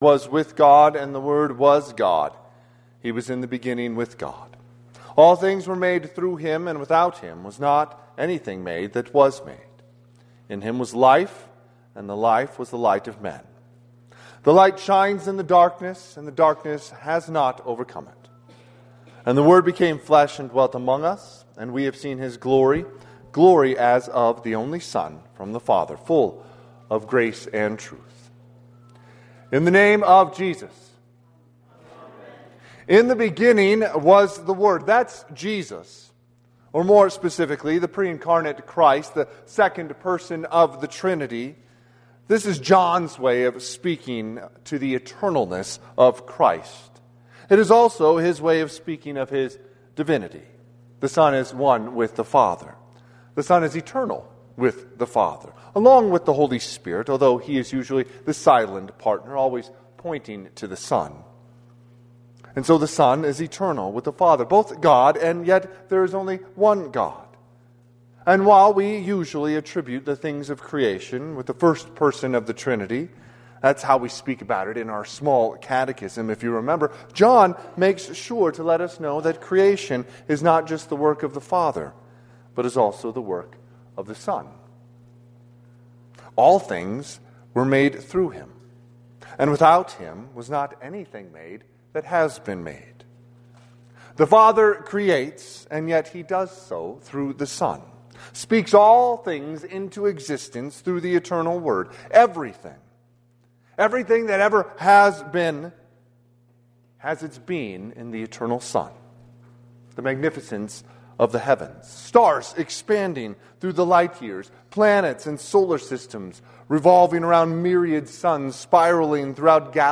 Sermon - 12/25/2025 - Wheat Ridge Evangelical Lutheran Church, Wheat Ridge, Colorado